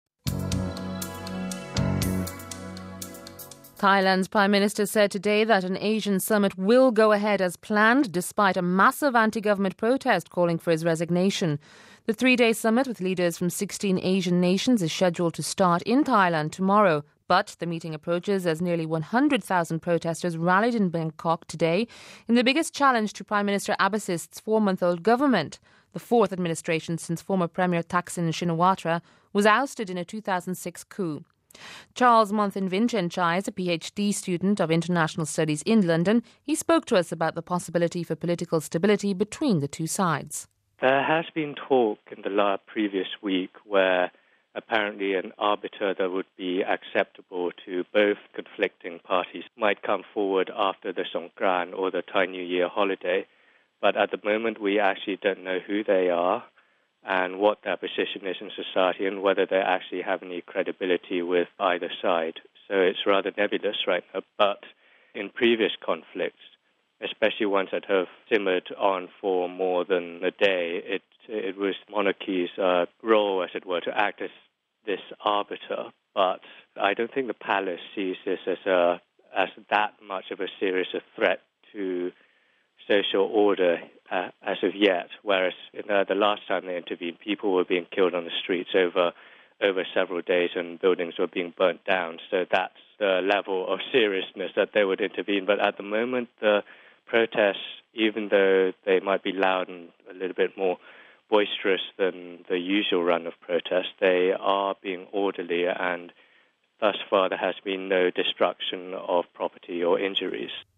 He spoke to us about the possibility for political stability between the two sides.